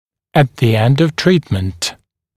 [ət ðə end əv ‘triːtmənt]][эт зэ энд ов ‘три:тмэнт]в конце лечения